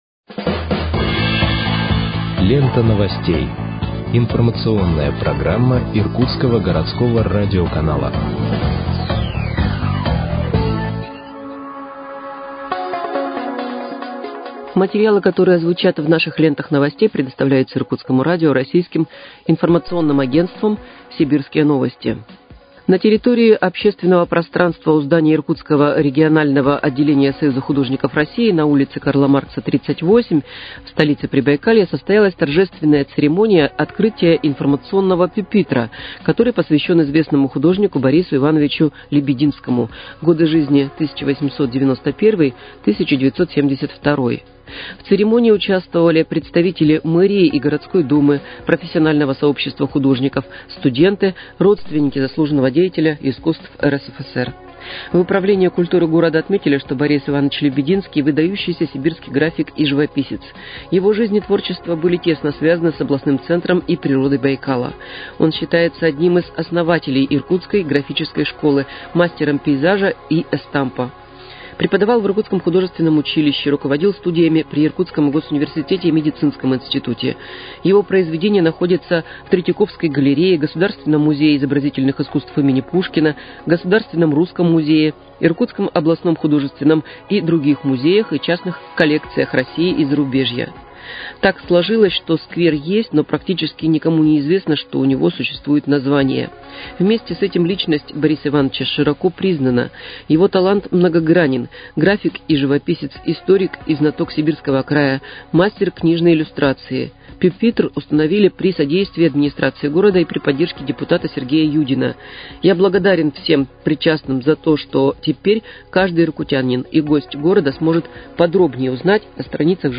Выпуск новостей в подкастах газеты «Иркутск» от 20.11.2025 № 1